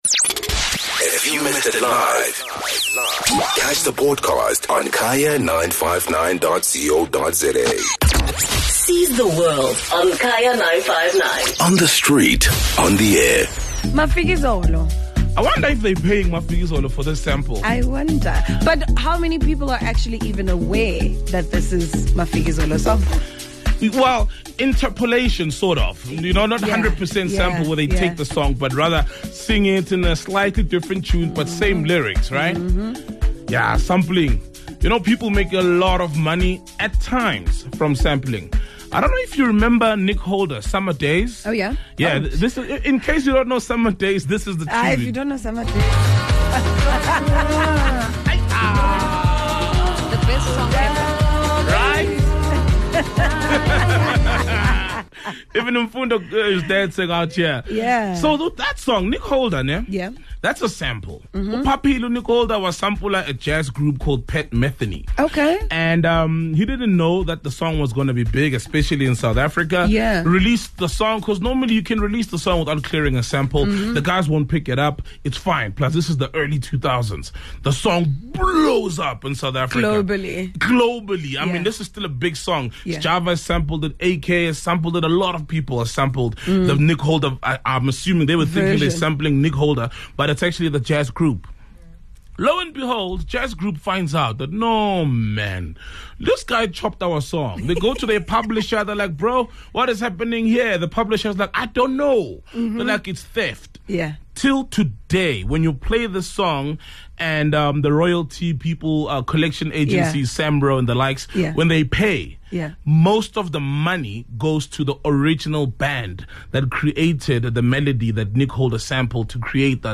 Listeners joined in, speaking about turning down opportunities to make money.